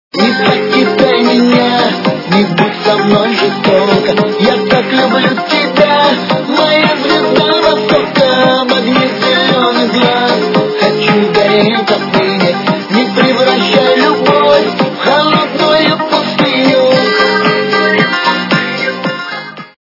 При заказе вы получаете реалтон без искажений.